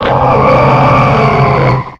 Cri de Wailord dans Pokémon X et Y.